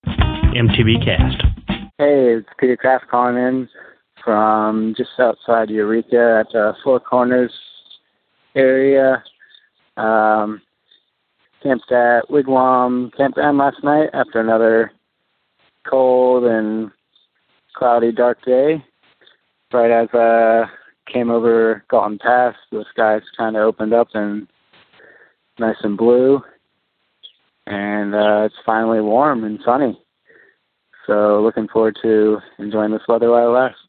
Posted in Calls , TD17 Tagged bikepacking , calls , cycling , MTBCast , TD17 , ultrasport permalink